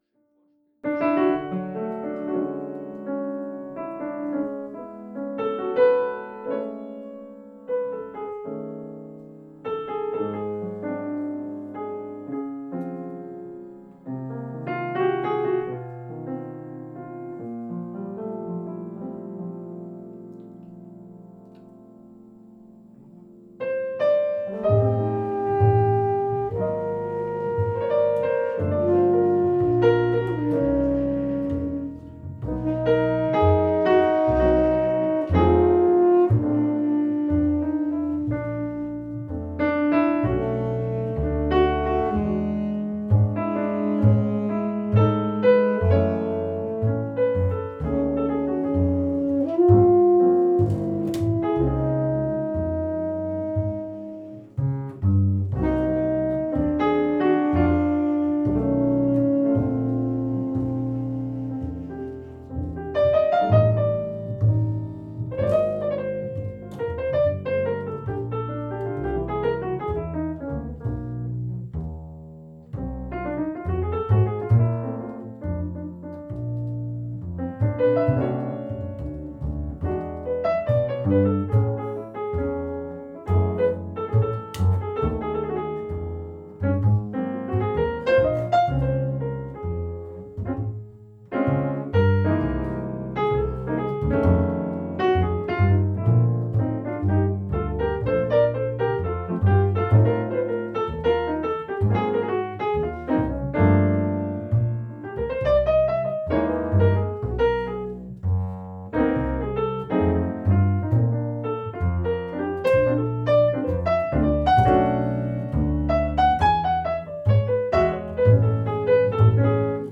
Trio-Projekte
Kammermusikalischer Jazz mit Sax, Piano, Bass